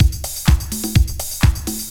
BC Beat 3_126.wav